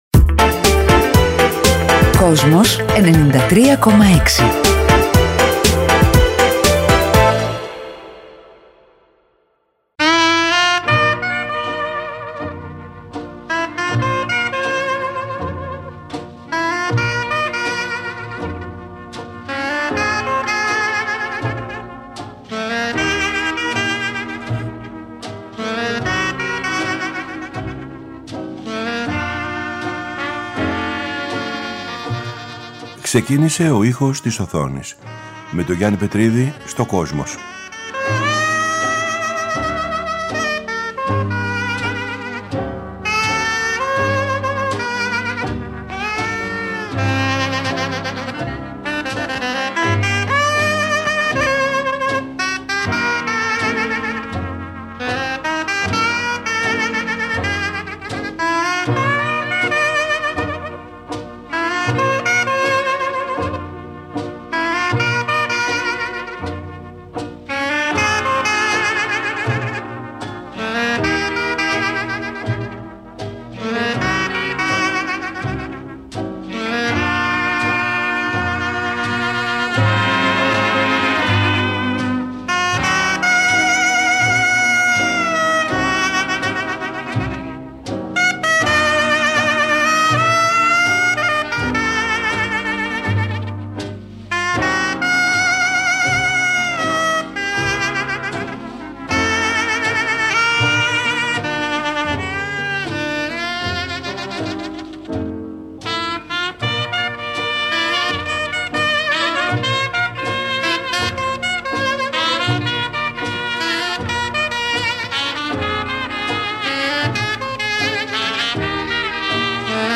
Από την Κυριακή 3 Δεκεμβρίου 2018 ξεκίνησε το αφιέρωμα του Γιάννη Πετρίδη στο Kosmos 93,6, με μουσική και τραγούδια που ξεκίνησαν την καριέρα τους από τον κινηματογράφο και, σε ένα δεύτερο στάδιο, από την τηλεόραση.